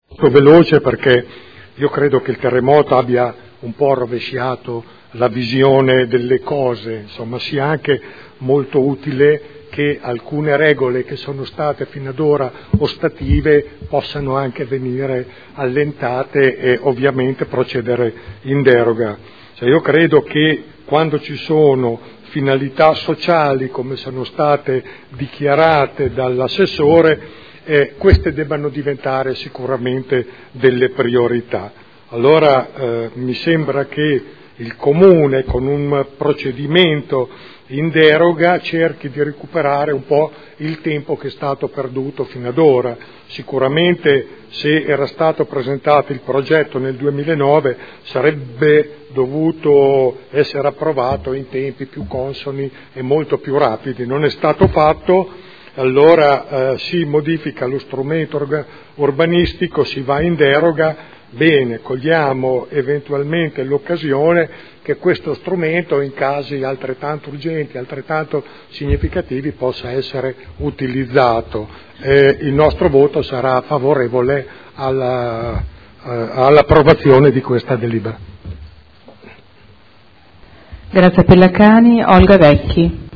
Seduta del 18/06/2012. Dibattito su proposta di deliberazione.